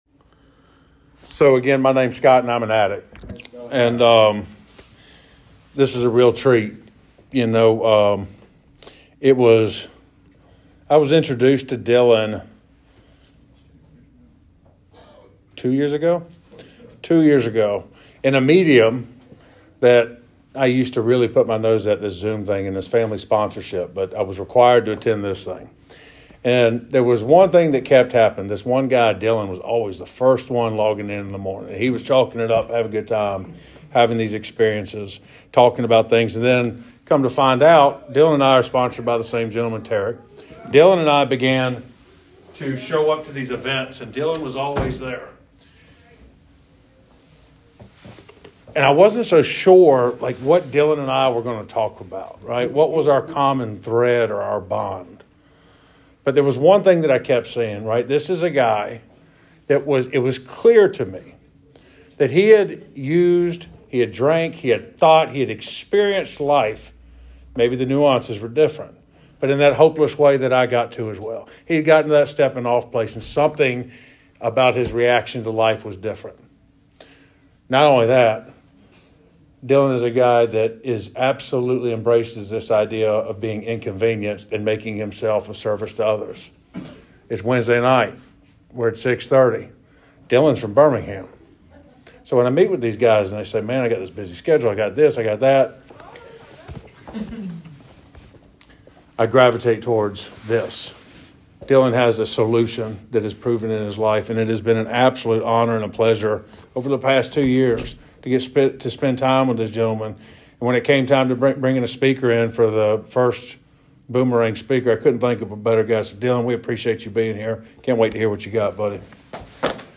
Speaking at Boomerang Group CA in Newnan, Ga